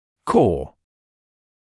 [kɔː][коː]основной, центральный; сердцевина, ядро